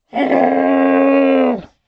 wolf_injured.wav